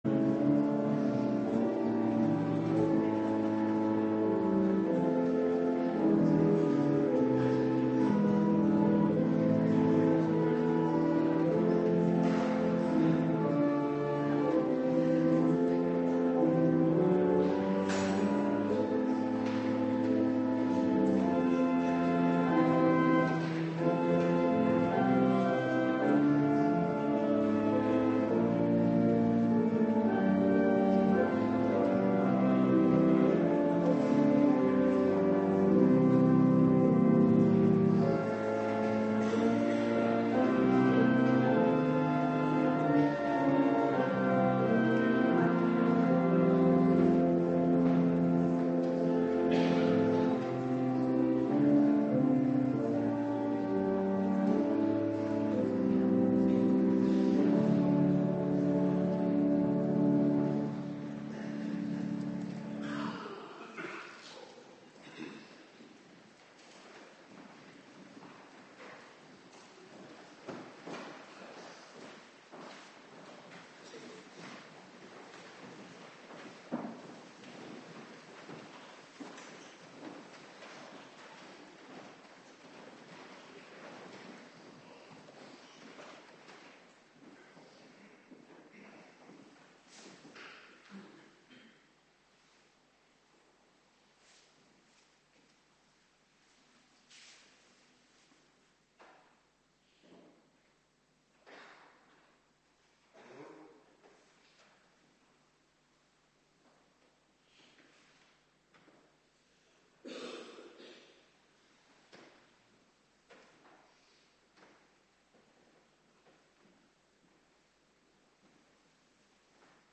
Woensdagavonddienst Voorbereiding Heilig Avondmaal